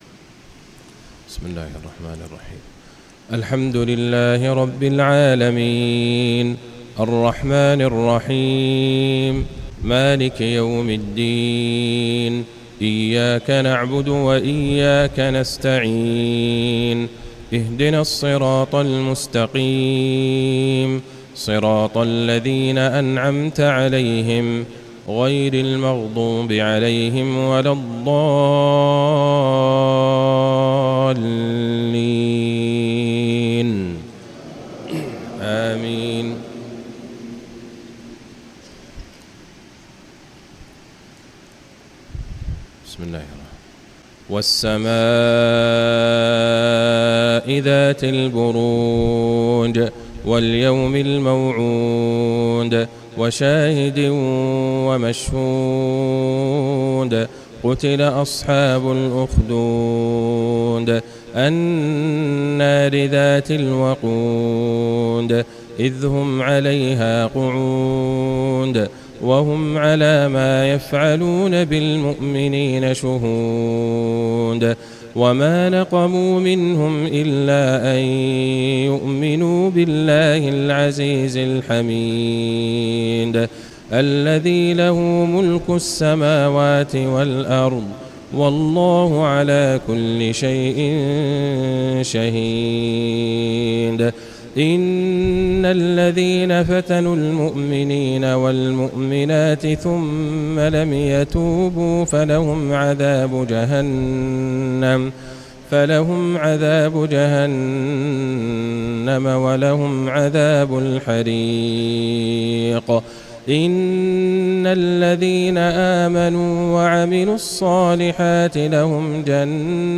مغرب الجمعة ٢٢ شعبان ١٤٤٦هـ للشيخ عبدالله البعيجان في مسجد كتشانغاني في دار السلام عاصمة تنزانيا
3. تلاوات وجهود أئمة الحرم النبوي خارج الحرم